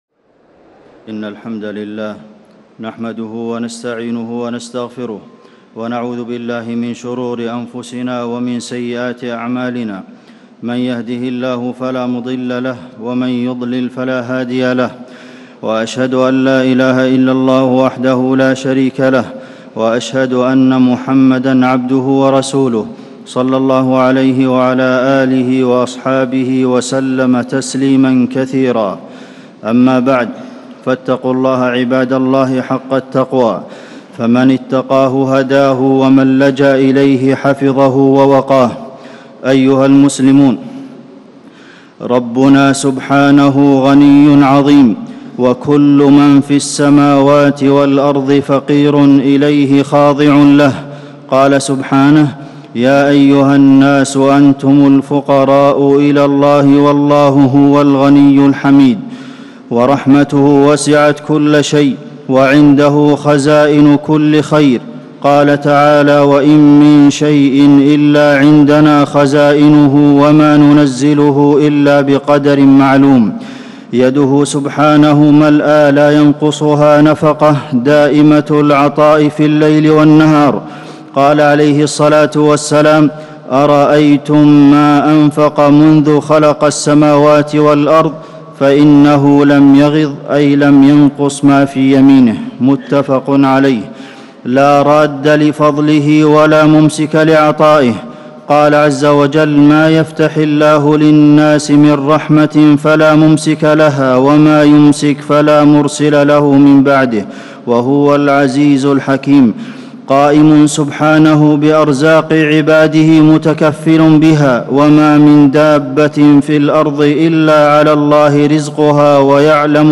خطبة الاستسقاء - المدينة- الشيخ عبدالمحسن القاسم
تاريخ النشر ١٦ صفر ١٤٤٠ هـ المكان: المسجد النبوي الشيخ: فضيلة الشيخ د. عبدالمحسن بن محمد القاسم فضيلة الشيخ د. عبدالمحسن بن محمد القاسم خطبة الاستسقاء - المدينة- الشيخ عبدالمحسن القاسم